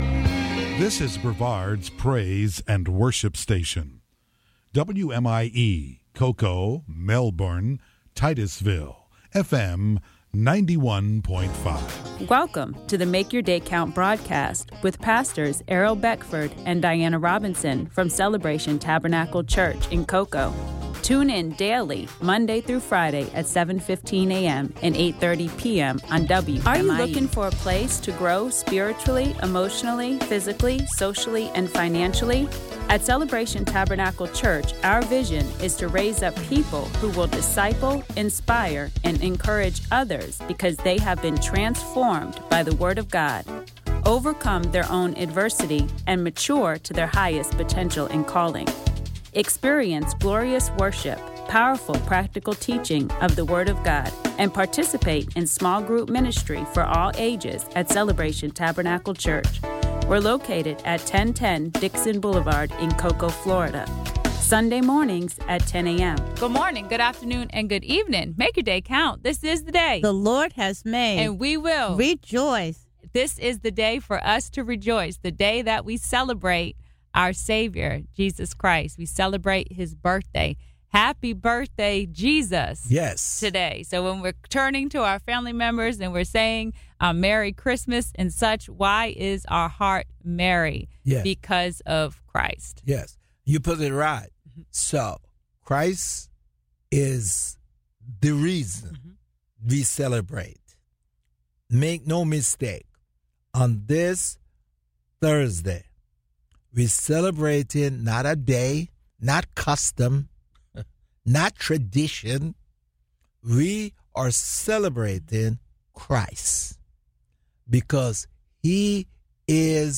Sermon; "Climate Change" Matthew Ch. 24 Part 2